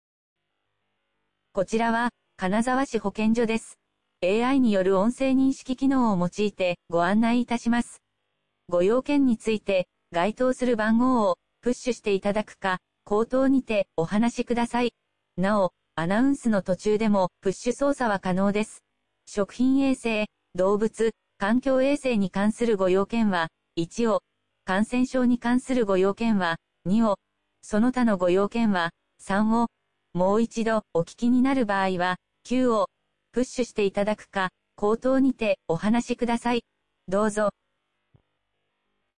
AI電話アナウンス導入部分 (音声ファイル: 229.7KB)